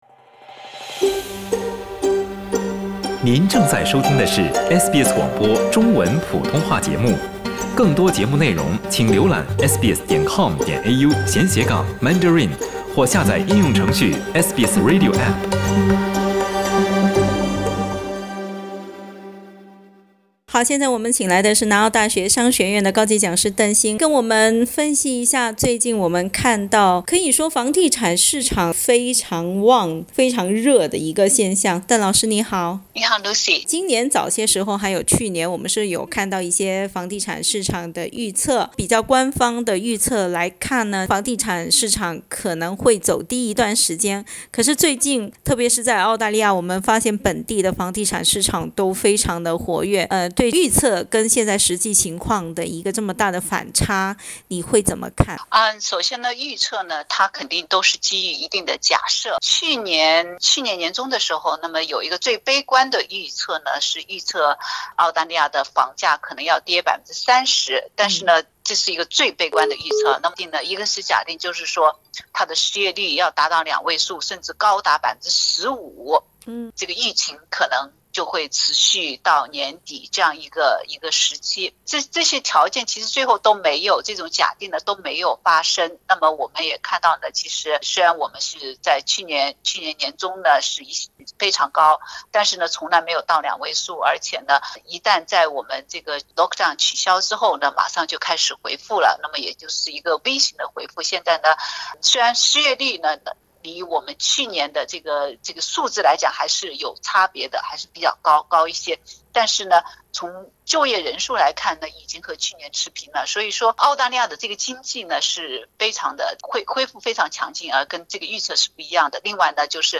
（请听采访，本节目为嘉宾观点，仅供参考） 澳大利亚人必须与他人保持至少1.5米的社交距离，请查看您所在州或领地的最新社交限制措施。